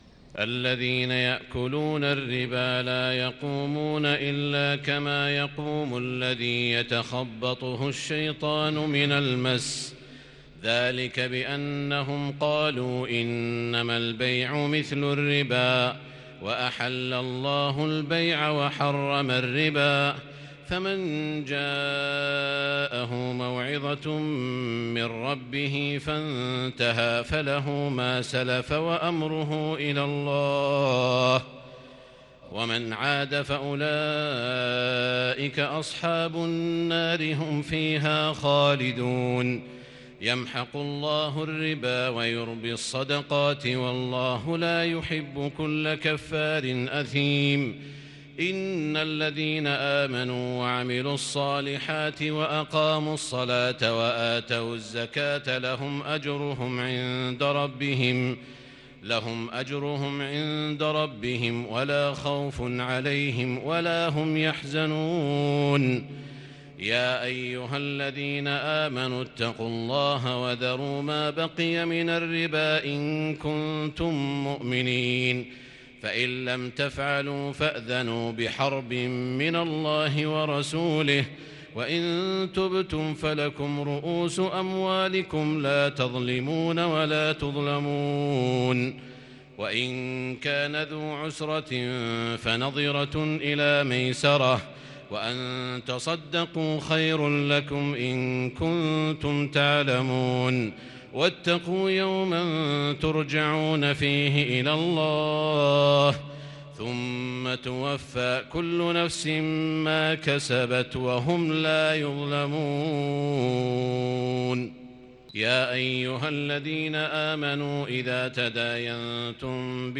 تراويح ليلة 4 رمضان 1441هـ من سورتي البقرة {275-286} و آل عمران {1-41} Taraweeh 4st night Ramadan 1441H Surah Al-Baqara & Surah Aal-i-Imraan > تراويح الحرم المكي عام 1441 🕋 > التراويح - تلاوات الحرمين